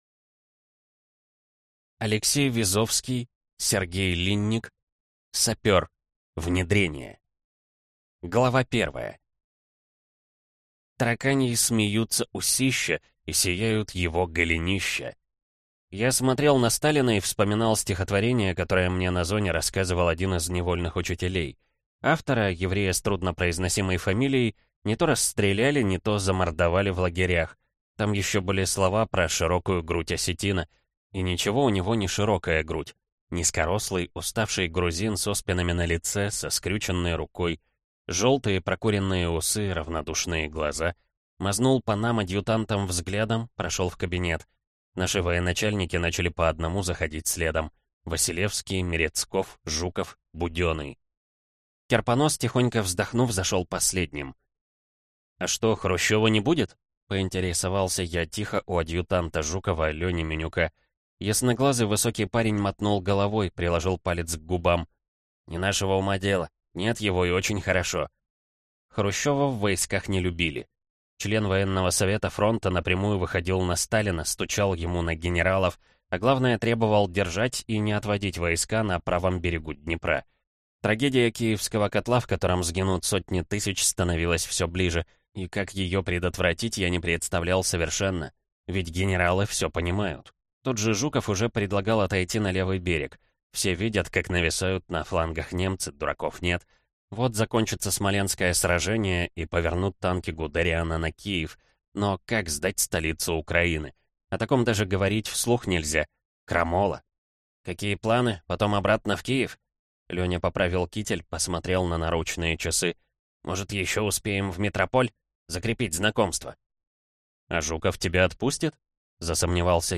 Аудиокнига Сапер. Внедрение | Библиотека аудиокниг